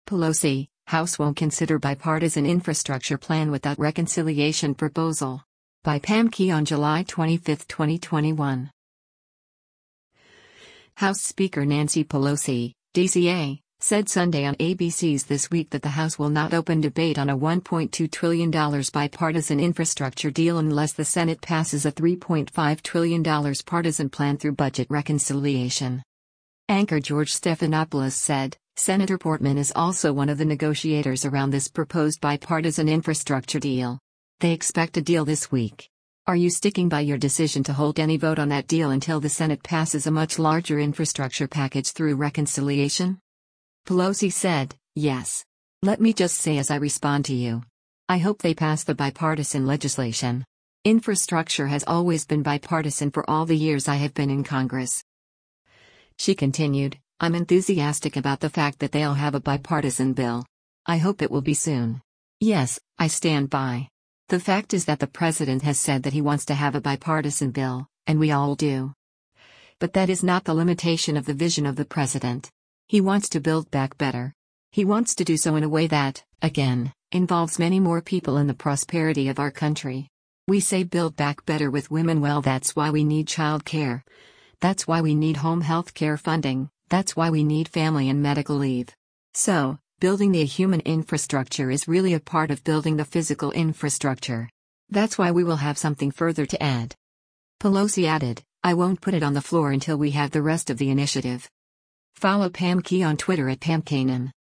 House Speaker Nancy Pelosi (D-CA) said Sunday on ABC’s “This Week” that the House will not open debate on a $1.2 trillion bipartisan infrastructure deal unless the Senate passes a $3.5 trillion partisan plan through budget reconciliation.